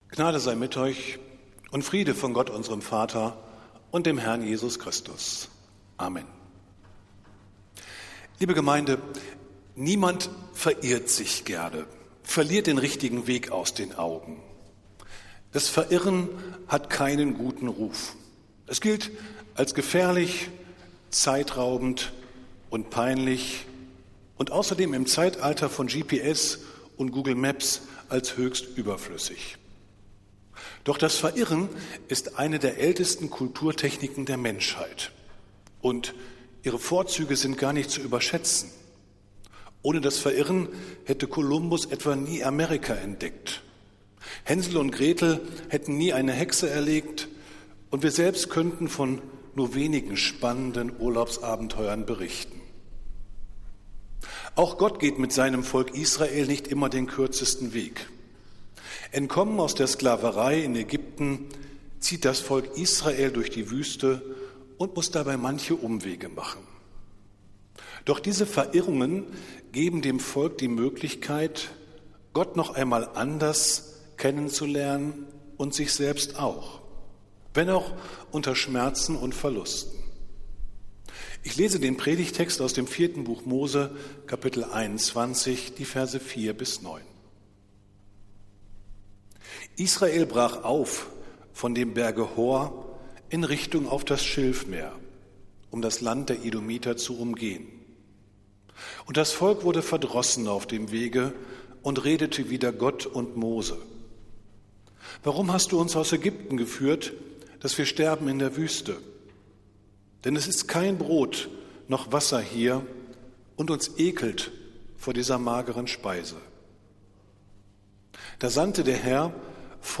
Predigt des Gottesdienstes aus der Zionskirche am Sonntag, den 25. Februar 2024